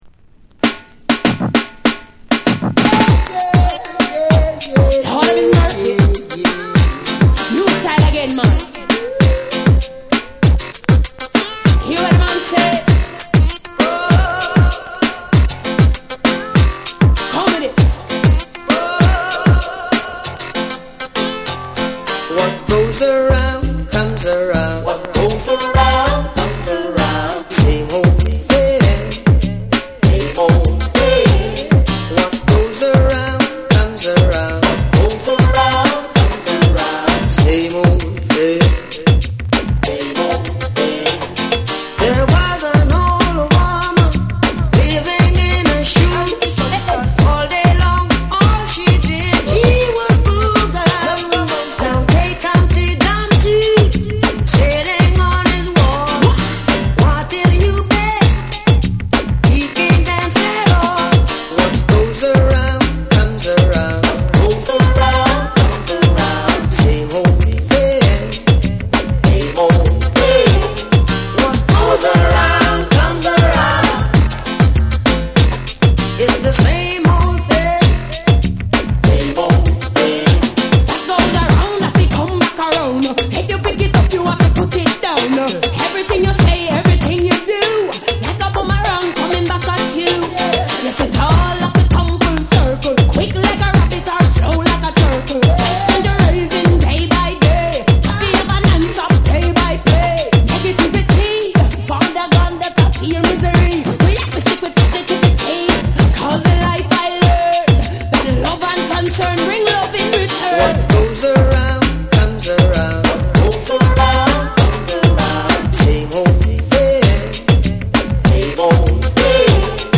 リージンな鍵盤が心地よいスムースなディープ・ハウスに仕立てた1。
REGGAE & DUB / TECHNO & HOUSE / BREAKBEATS